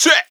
VR_vox_hit_check.wav